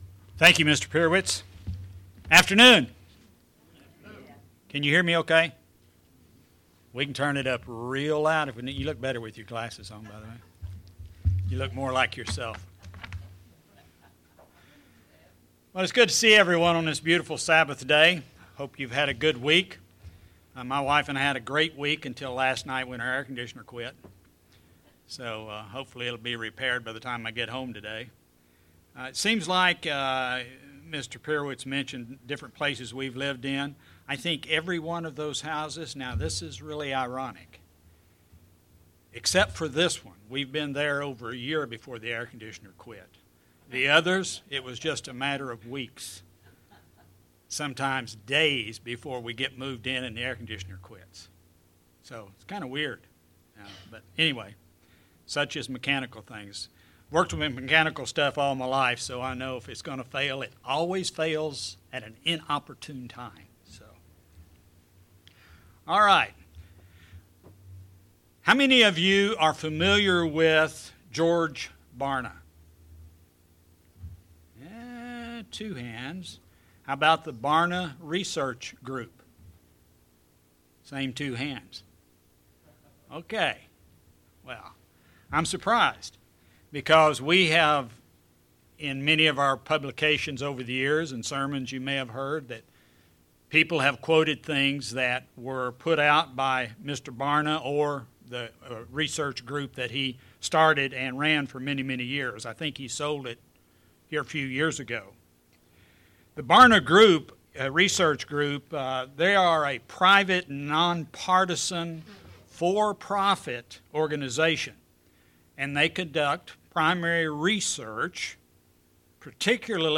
In this sermon, the speaker looks into the subjects of casual and captive Christians. As members of God's church, we need to be actively participating in God's plan for mankind, rather than becoming a simple casual Christian we need to be focused, staying a captive Christian.